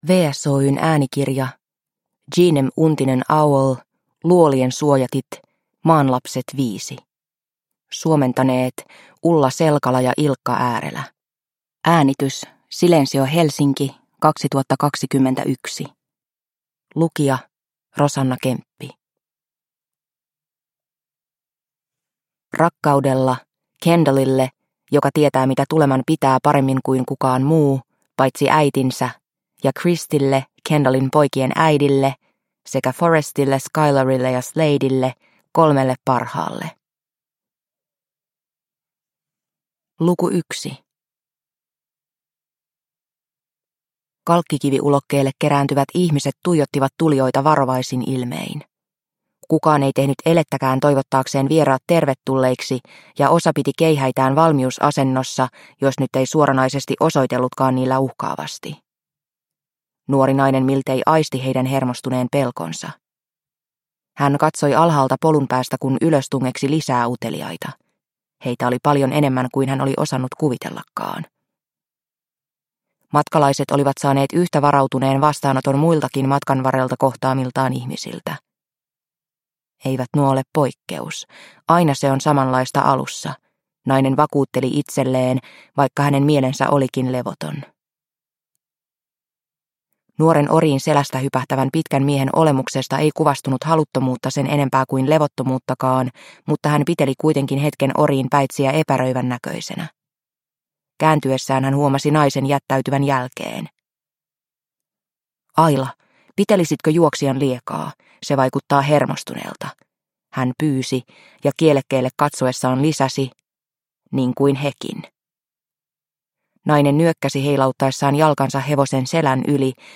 Luolien suojatit – Ljudbok – Laddas ner